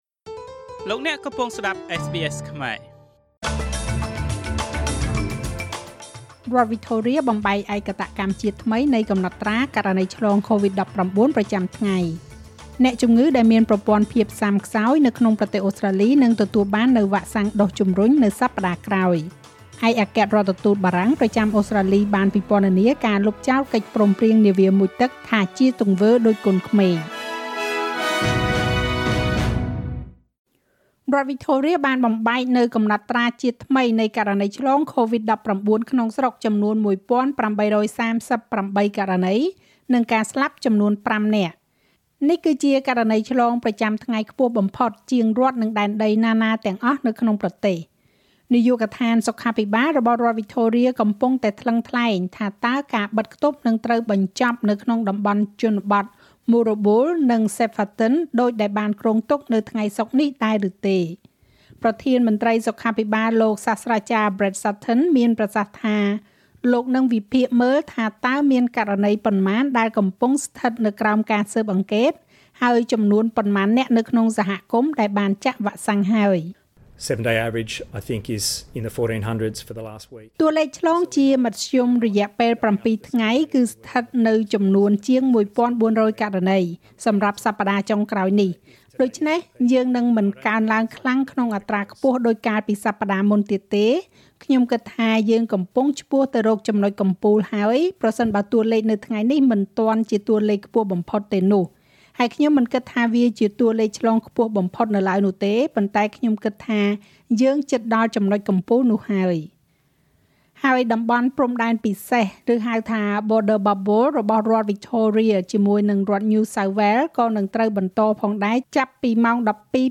ស្តាប់មាតិកាព័ត៌មានចុងក្រោយបង្អស់ក្នុងប្រទេសអូស្រ្តាលីពីវិទ្យុSBSខ្មែរ។